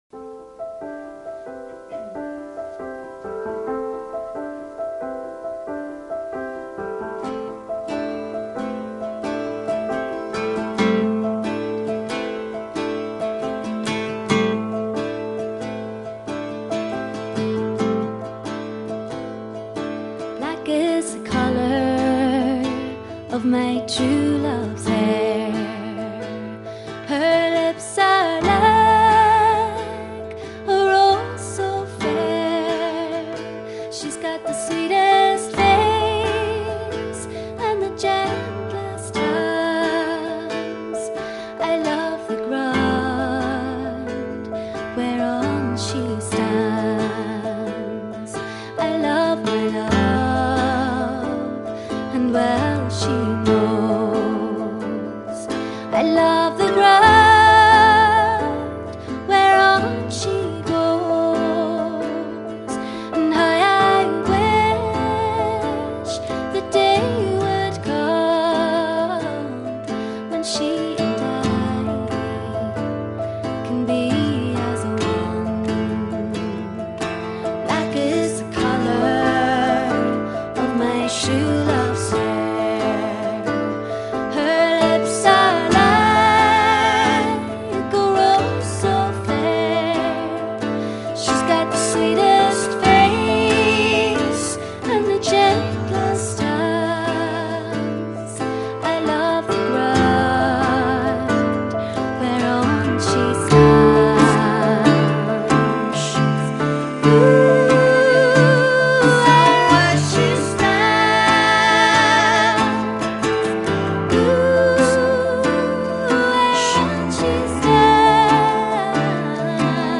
几乎所有的歌曲都是具有传统习俗风格的
很好听啊， 声音不错，唱功也好，谢谢分享哦